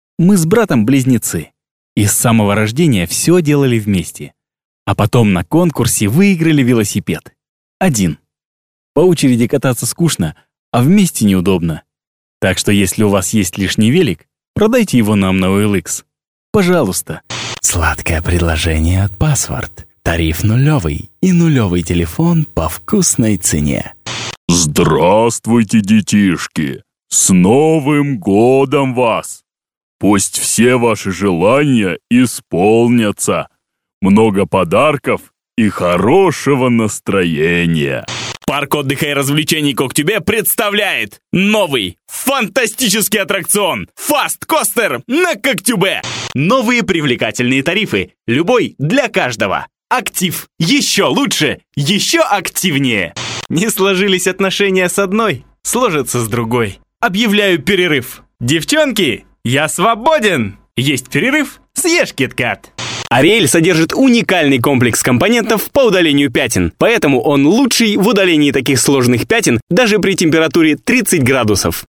Статус: Диктор доступен для записи.
Если вам нужен молодёжный, зажигательный голос, то милости прошу.
Микрофон Audio-Technica AT-4033a, предусилитель dbx-376